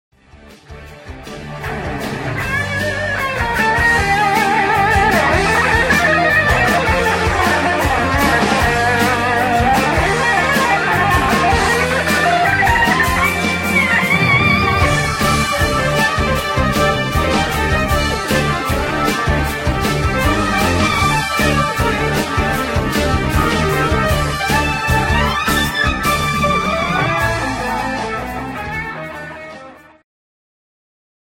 ジャンル HardRock
Progressive
シンフォニック系
シンフォニック・プログレとメロディック・ハード/AORの融合。